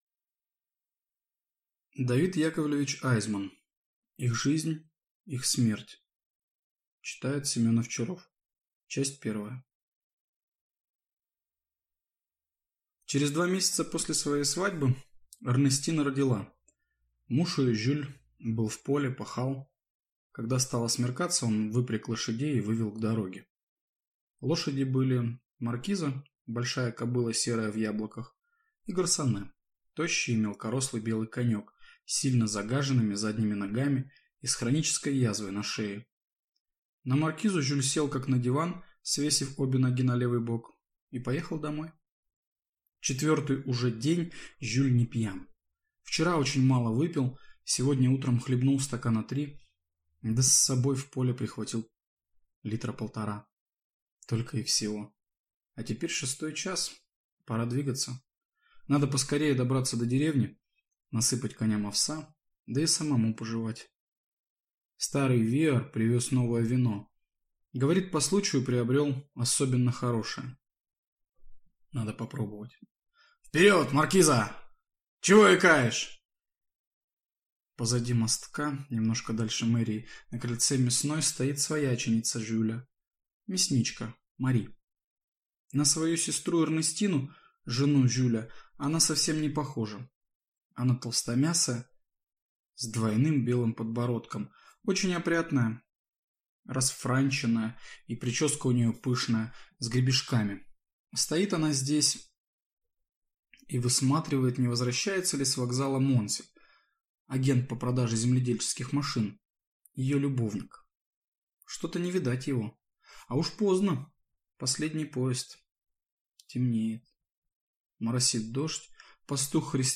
Аудиокнига Их жизнь, их смерть | Библиотека аудиокниг